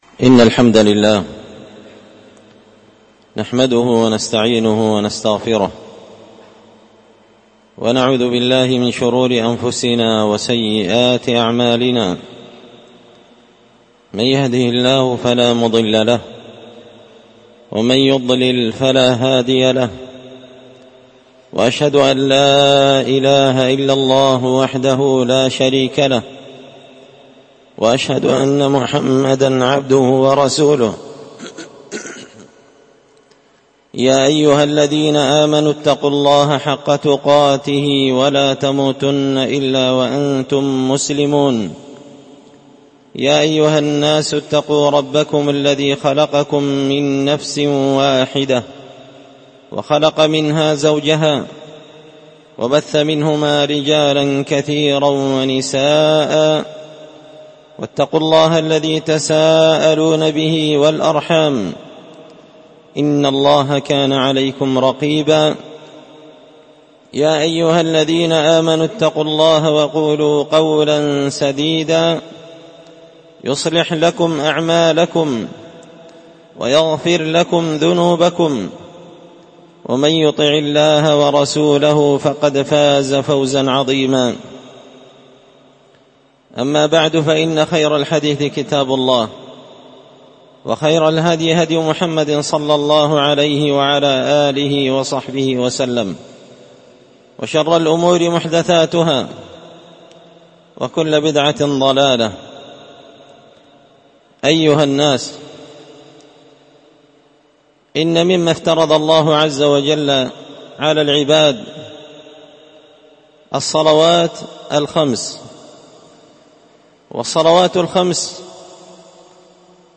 خطبة جمعة بعنوان شروط الصلاة
ألقيت هذه الخطبة بدار الحديث السلفية بمسجد الفرقان قشن-المهرة-اليمن